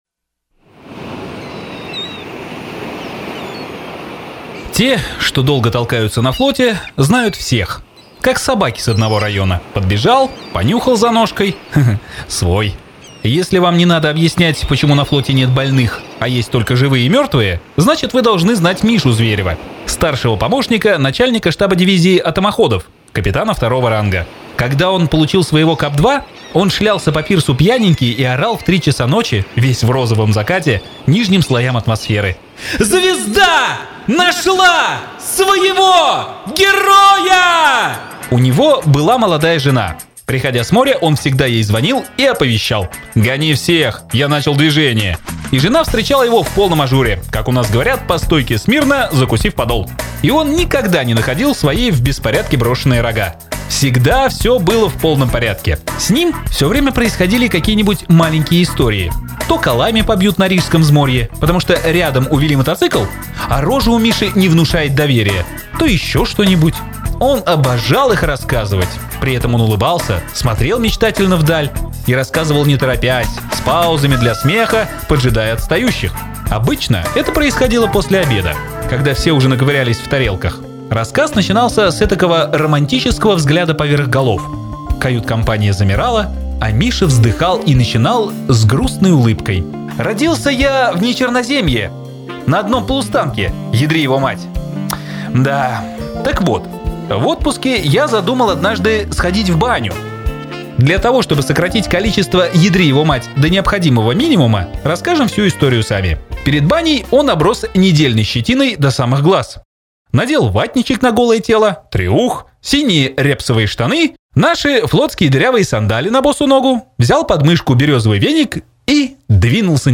Лет несколько назад возникла у меня мысль озвучить один из рассказов.
В связи с этим представляю подкаст по рассказу «Я — Зверев!» из книги «Расстрелять»: